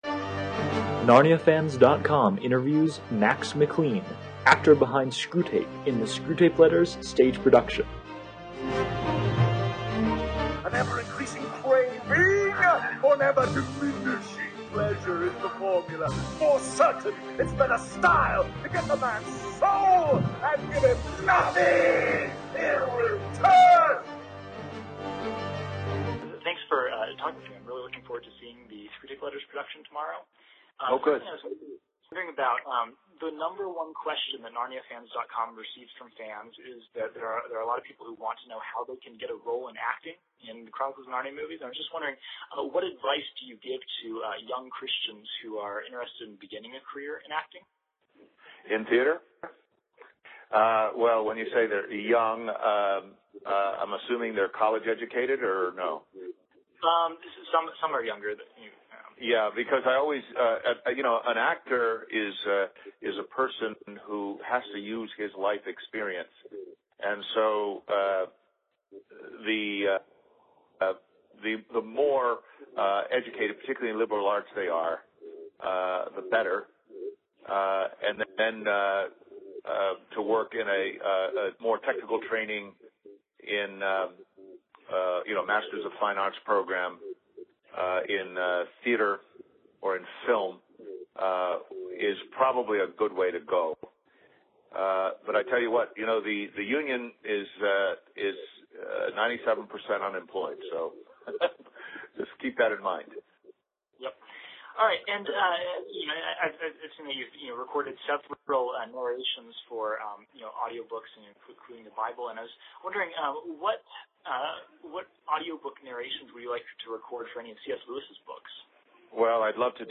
01-Screwtape-Interview.mp3